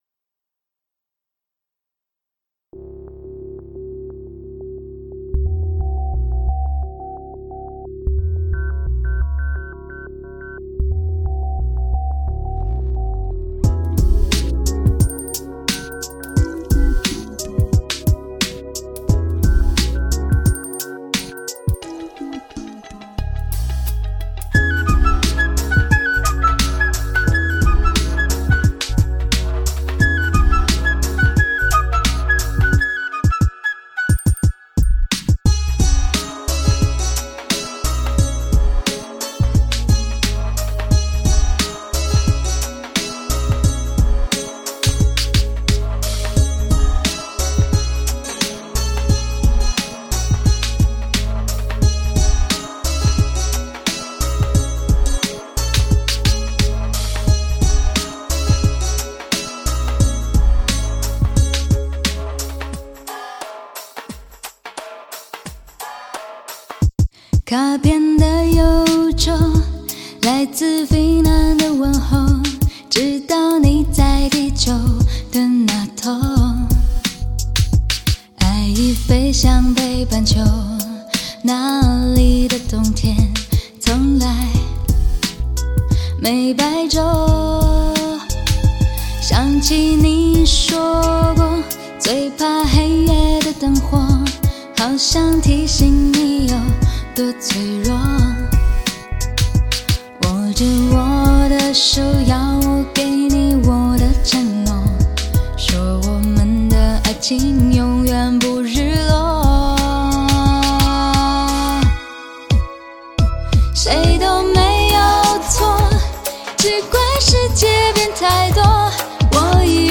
德国黑胶CD
兼容黑胶的高保真和CD的低噪音
CD5 动感节奏